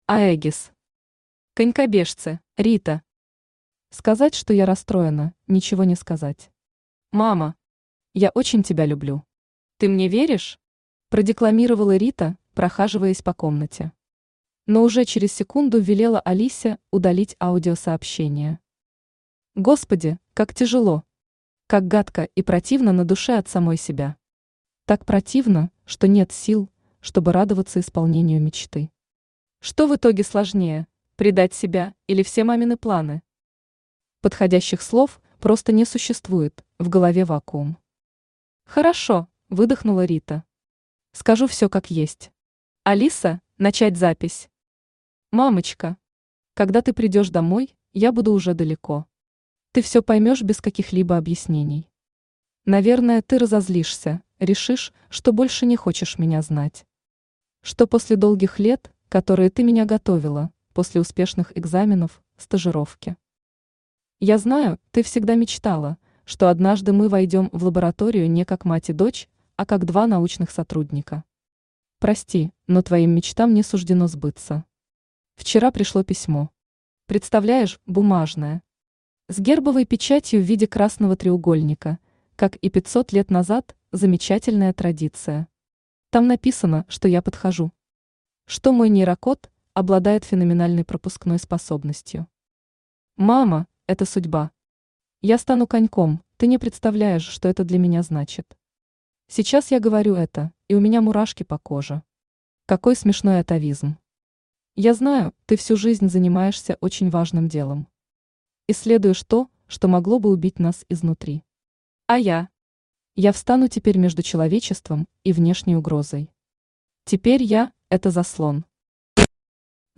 Аудиокнига Конькобежцы | Библиотека аудиокниг
Aудиокнига Конькобежцы Автор Аэгис Читает аудиокнигу Авточтец ЛитРес.